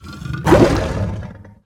Dragons roar and goblins squeak.